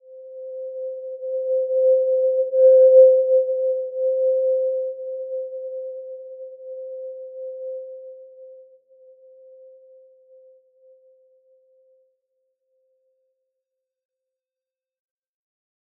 Simple-Glow-C5-mf.wav